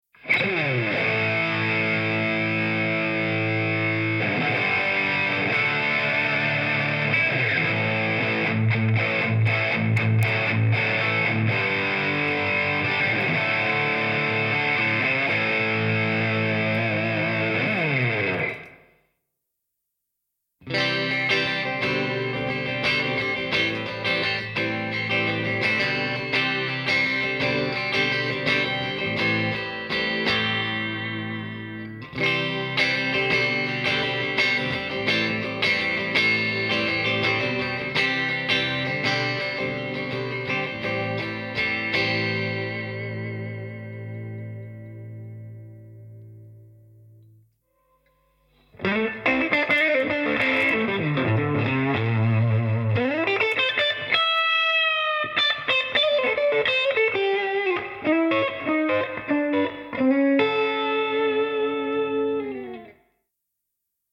Un cabinet amplificato full-range (FR/FR) con altoparlante da 12 pollici e tweeter creato per l'ecosistema TONEX e che si integra perfettamente con TONEX Pedal e TONEX ONE.
Tonex-Cab-ESEMPI-AUDIO-MP3.mp3